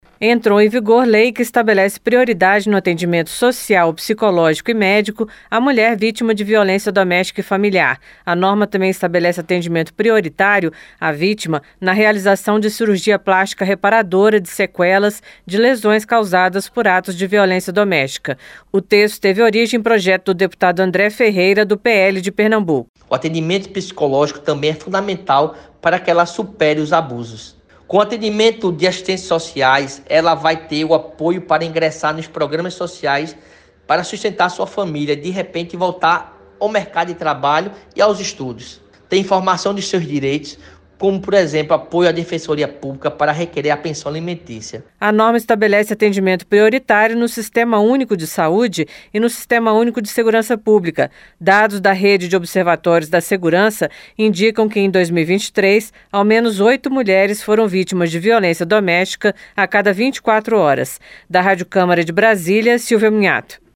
Lei garante atendimento prioritário para a mulher vítima de violência - Radioagência